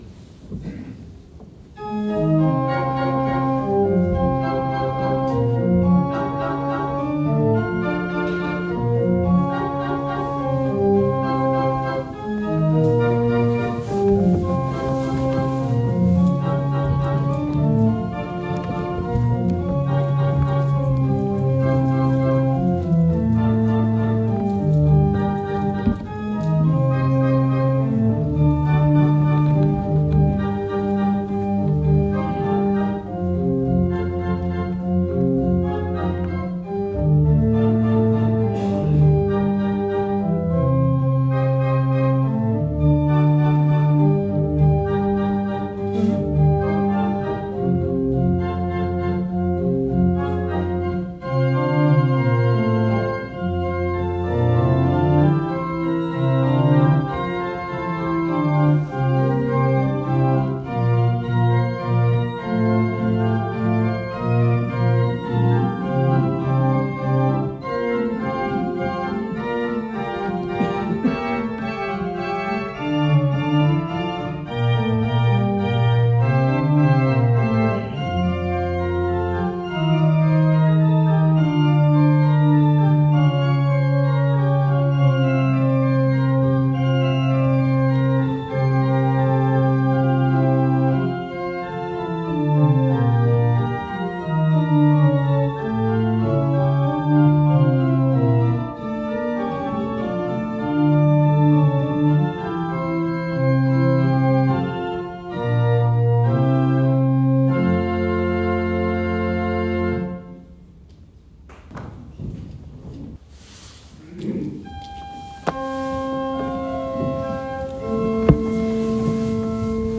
Unser Ziel war es, wieder einen möglichst barocken Klang herzustellen, soweit es die teilweise sehr mitgenommenen Pfeifen zuließen.
Im Pedal wurde ein neuer Subbass 16' angefertigt, da die alten Pedalpfeifen nicht mehr zu retten waren.
Achering-Einweihungsmesse-kurz.mp3